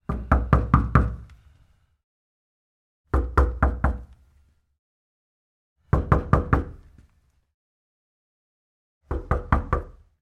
敲门回音
描述：我在家里敲大门的录音的一个严重风格化的处理版本。
标签： 龟裂 回声 捶击
声道立体声